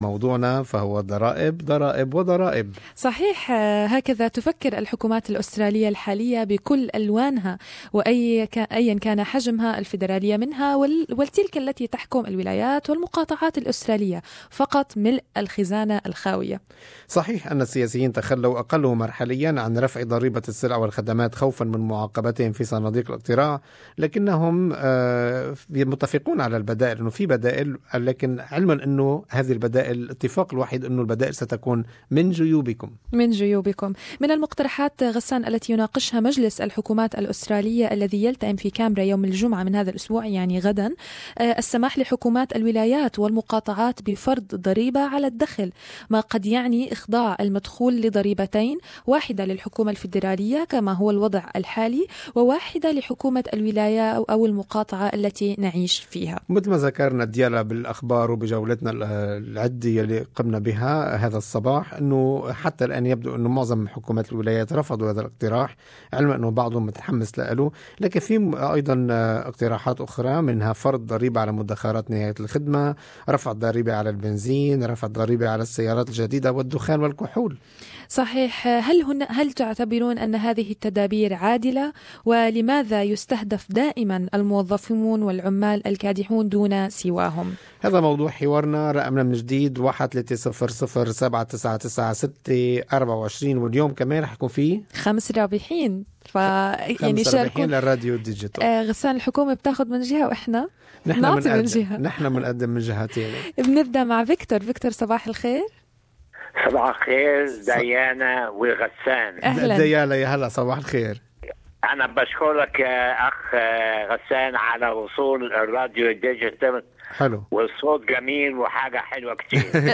Talkback listeners opinions